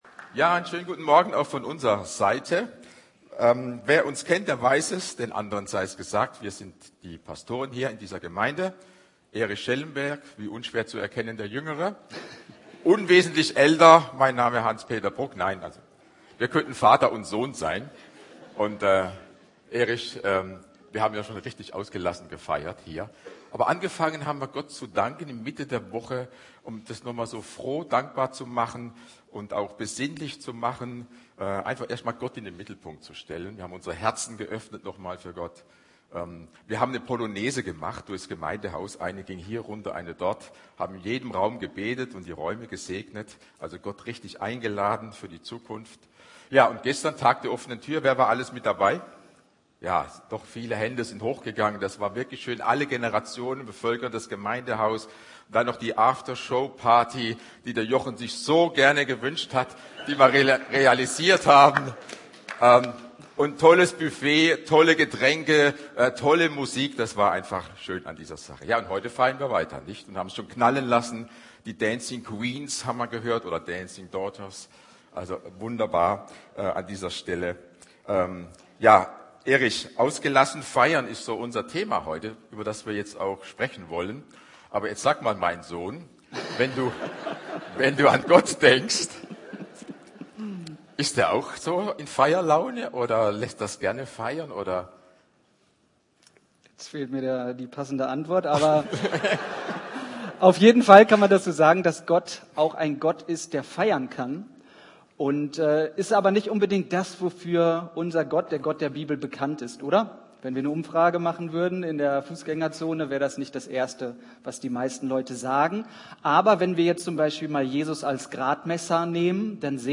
Predigt
Mehr Informationen Inhalt entsperren Erforderlichen Service akzeptieren und Inhalte entsperren Anschauen Anhören Speichern Einweihungsgottesdienst vom 14.03.2026. Predigt mit dem Thema „Ausgelassen feiern“ zum 5.